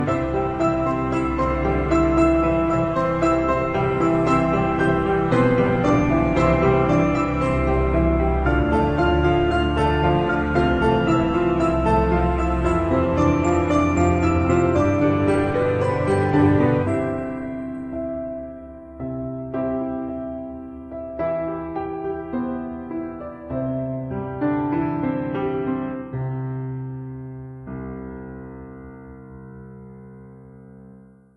campanaditas.mp3